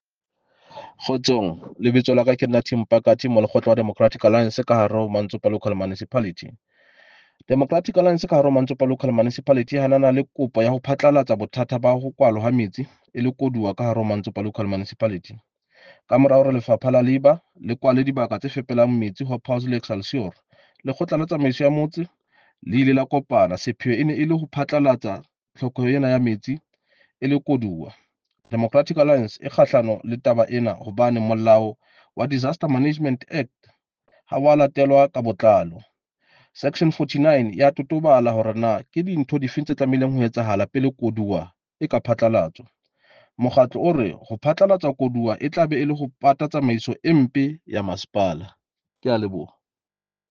Sesotho soundbites by Cllr Tim Mpakathe and